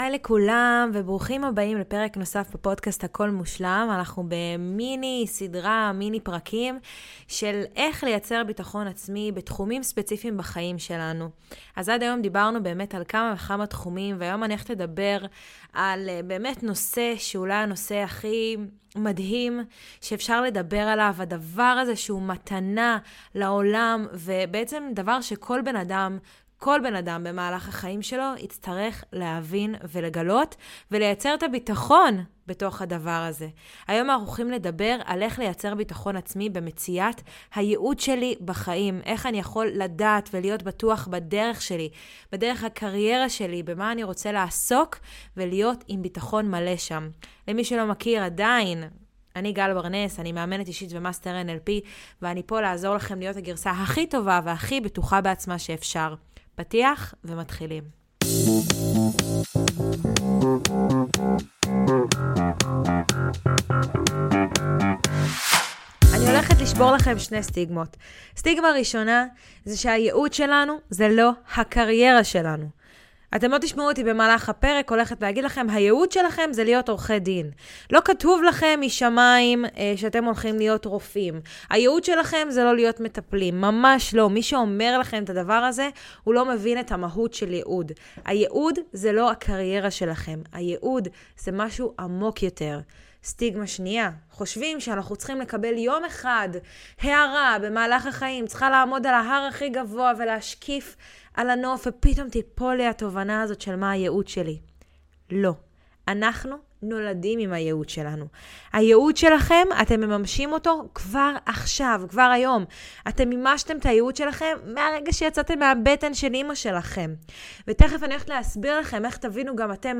מיני עונה של פרקי סולו קצרים וממוקדים שיעזרו לכם לפתח ביטחון עצמי במקומות ספציפיים בחייכם!